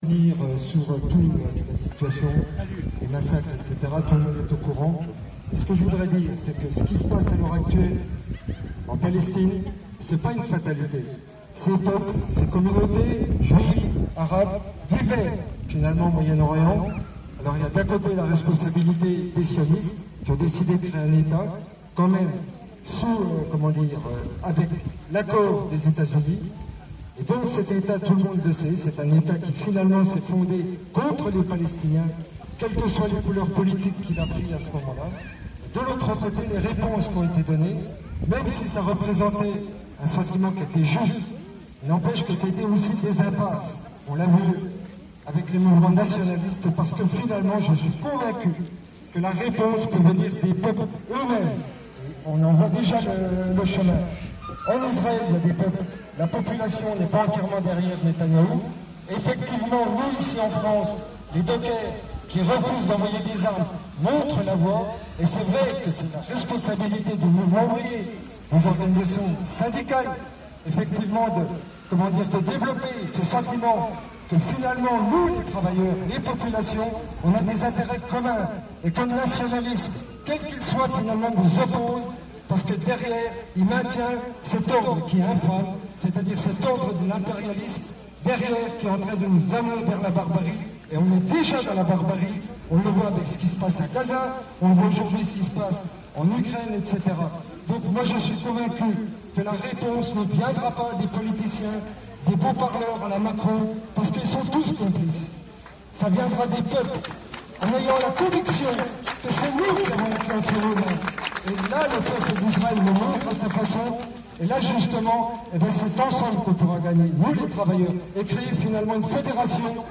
Rassemblement sur la Palestine le 14 juin 2025 : Belfort